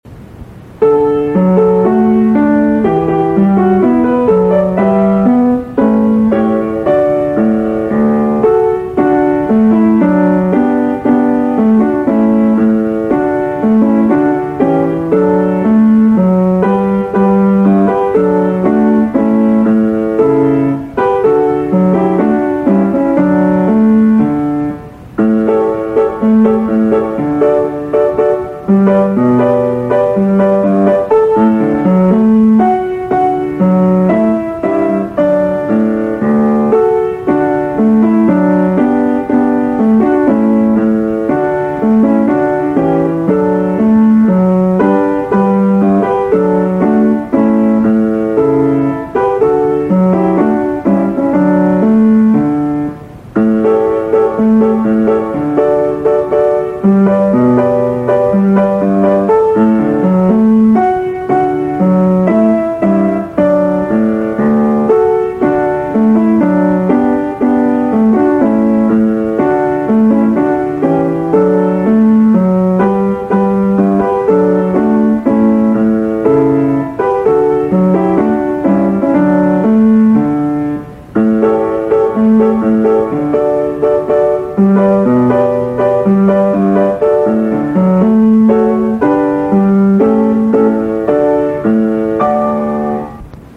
久世西小学校 校歌